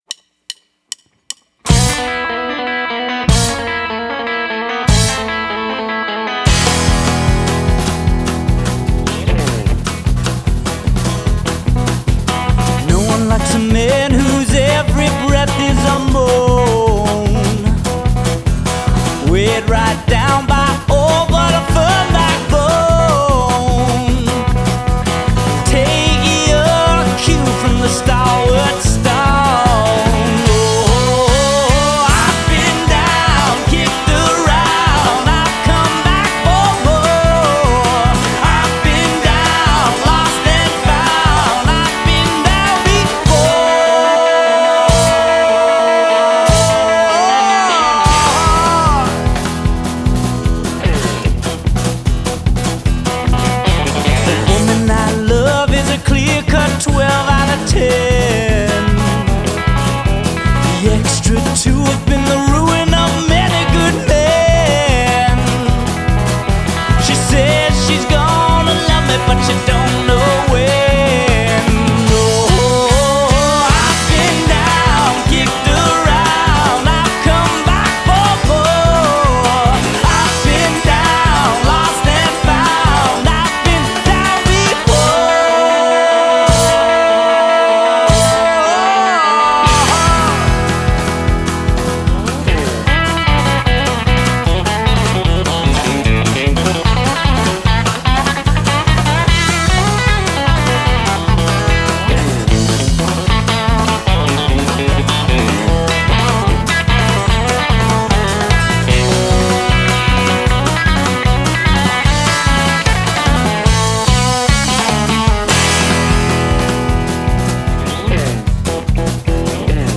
bluegrass
and no drum editing… what you hear is exactly what I played!